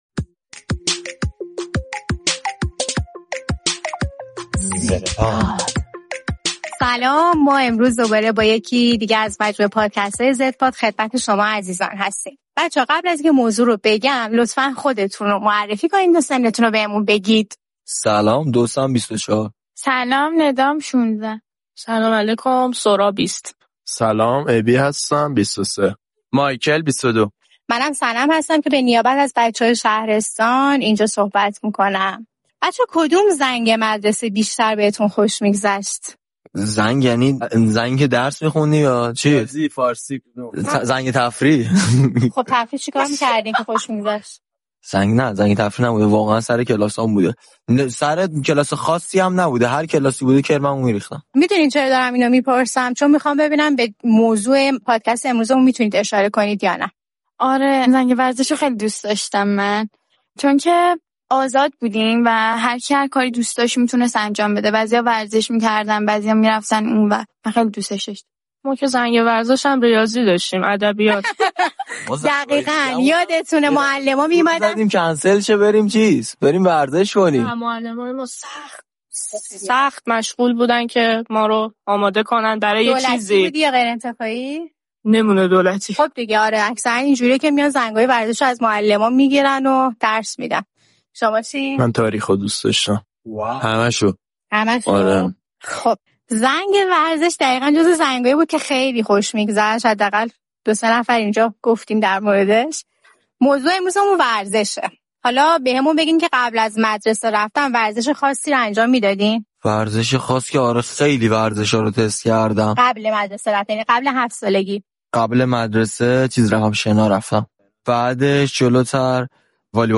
این قسمت زدپاد گفت‌وگو گروهی از بچه‌های نسل زد درباره رابطه آن‌ها با ورزش است. اینکه آیا ورزش نقش مهمی در زندگی این بچه‌ها دارد.